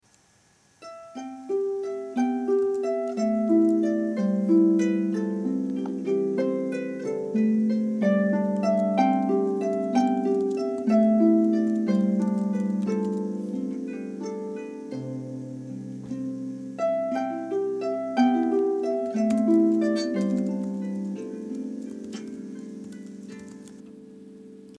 Presented for 26-string harp and larger lever harp.
There is a modulation to D as a coda-type ending.